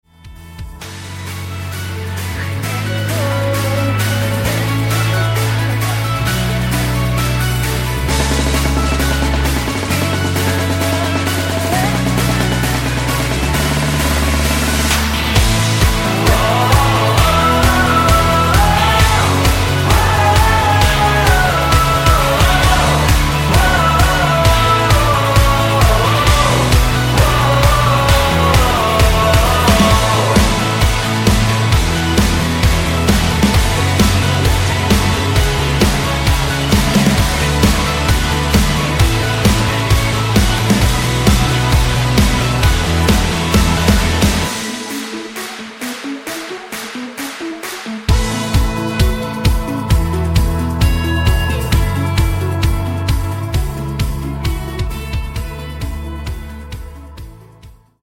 음정 원키 3:37
장르 가요 구분 Voice MR